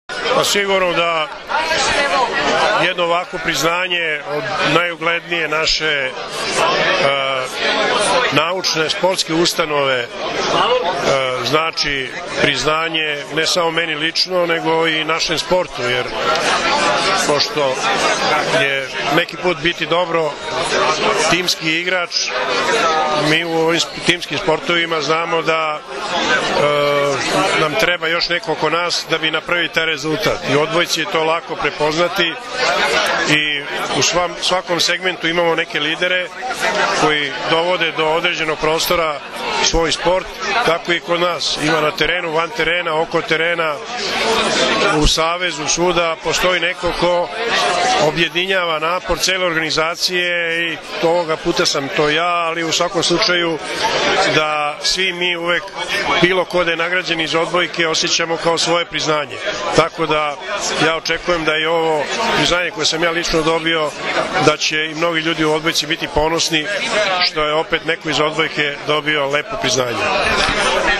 Svečana sednica je održana u amfiteatru Fakulteta, uz prisustvo mnogobrojnih gostiju, studenata i profesora.
IZJAVA